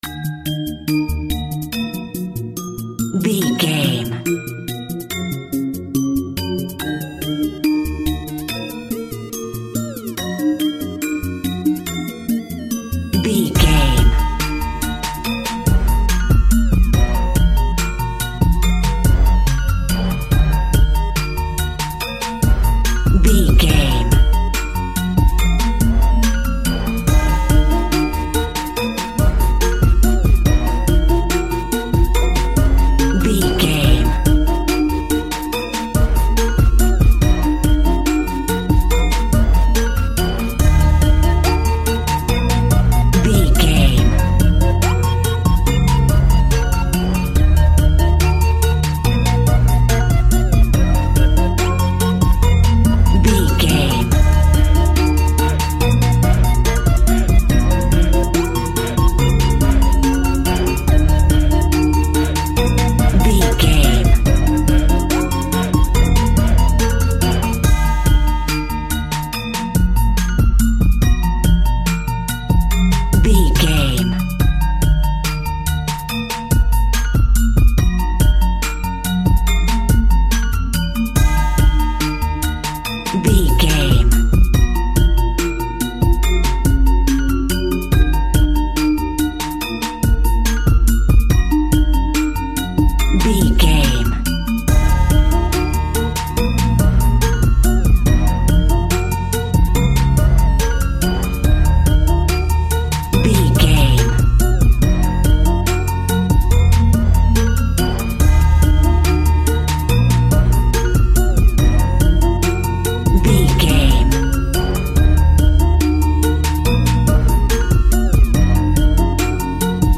Aeolian/Minor
E♭
instrumentals
chilled
laid back
groove
hip hop drums
hip hop synths
piano
hip hop pads